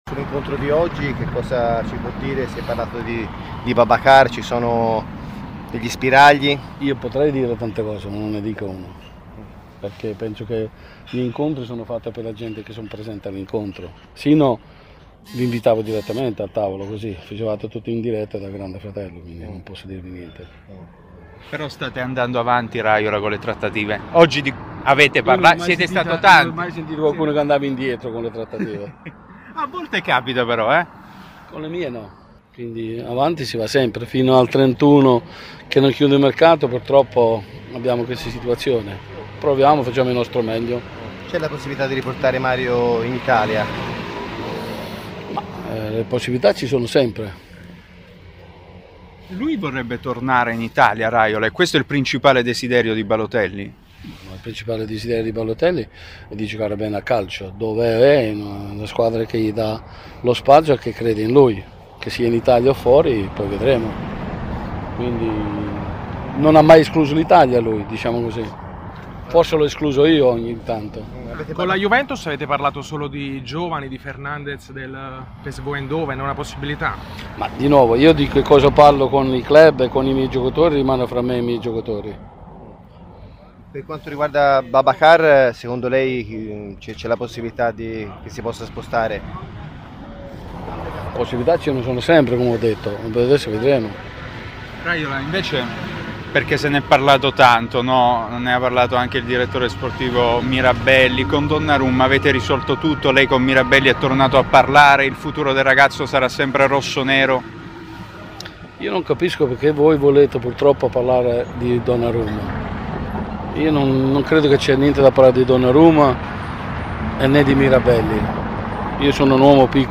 Mino Raiola, procuratore, sul mercato dei suoi assistiti, su Mirabelli, sui tempi del mercato. Intervista